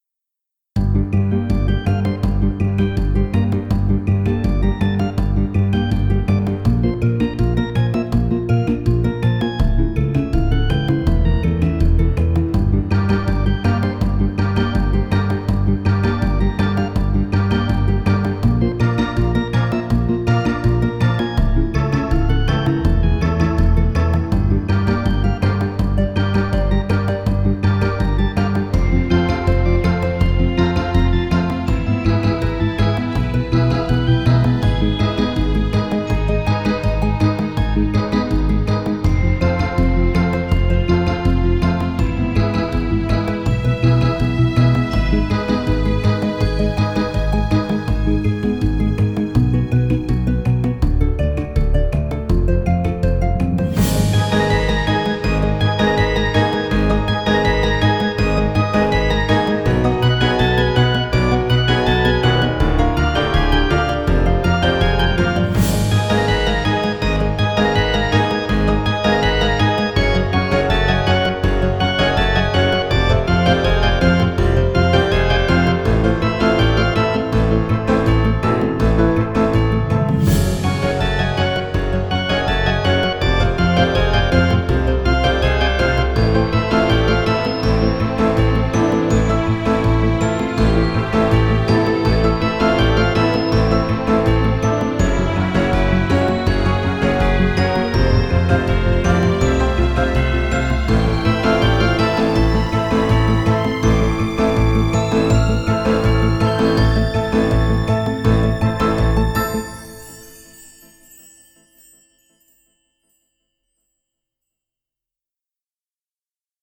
Electronic / 2009